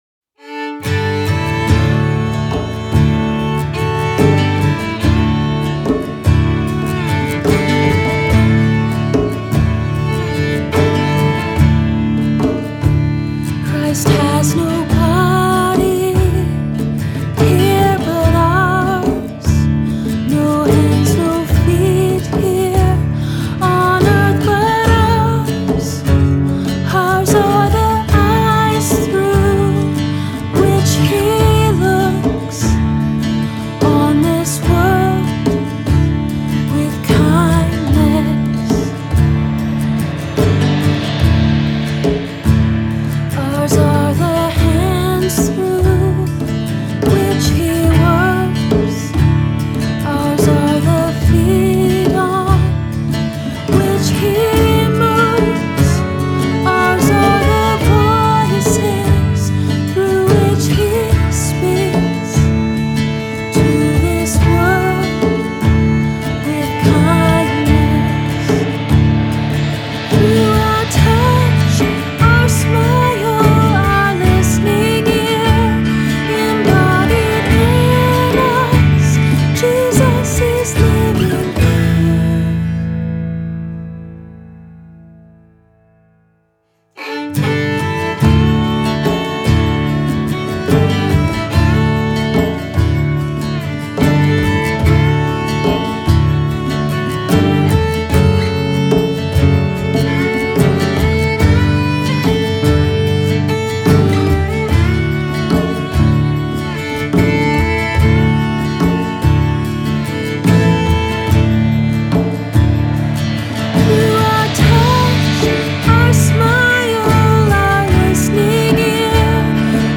This is a great benediction/sending song